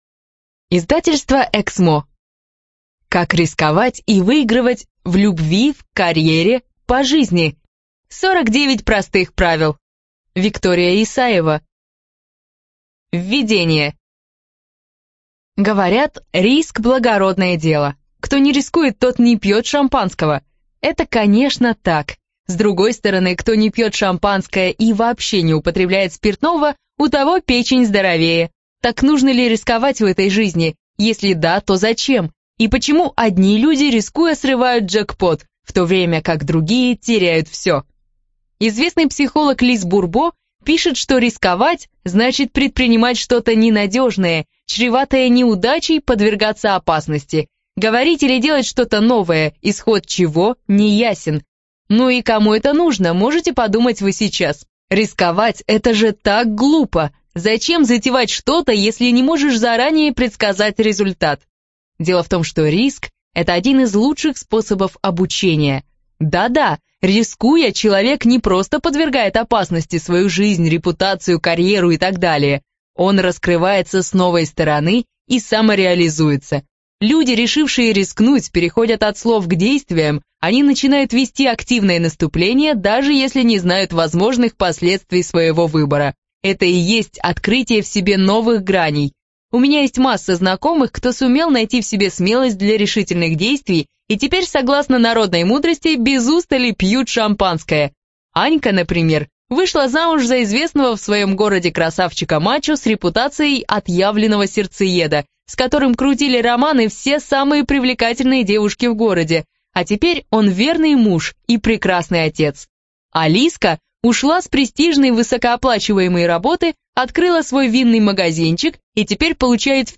Студия звукозаписиЭКСМО